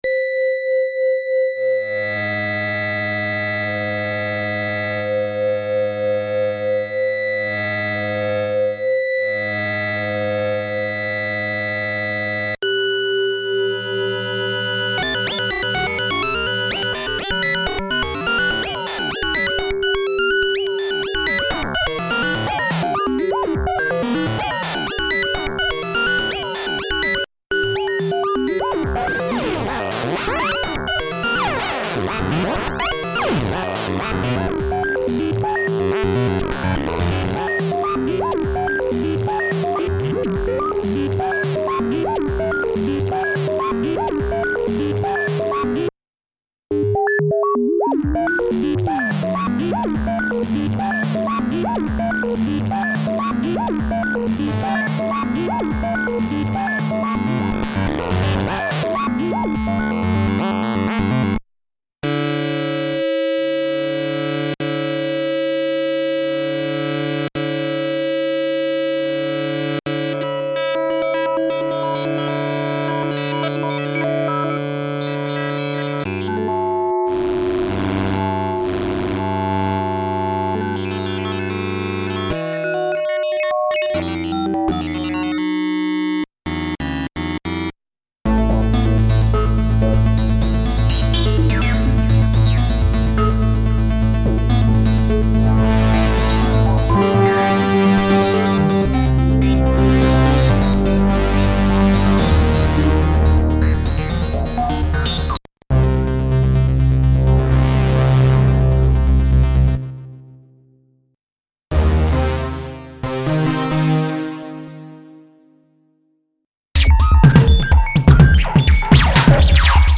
something with Wavetables controlled by the Joystick testing the Wavetable-OSC model and another OSC modulating it
Einige Joystick-OSC-Morphs, in der Mitte Wavetable Klänge aus dem Origin Wavetable OSC mit manueller Wavetableveränderung und weiterem Oszillator dazu
origin_wavetable15.mp3